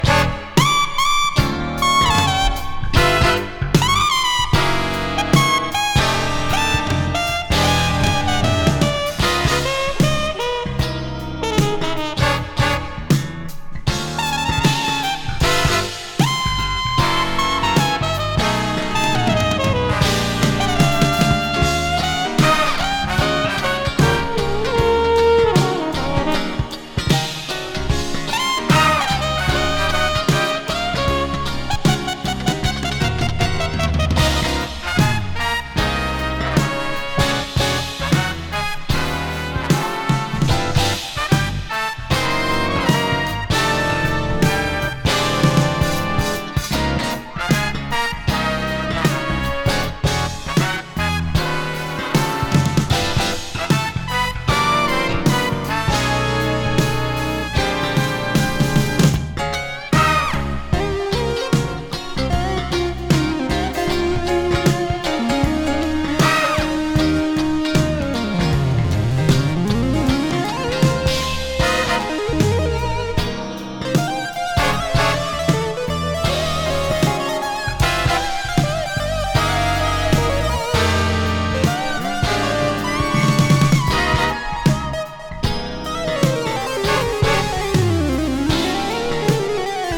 エレクトロ・ジャズファンクC5が◎！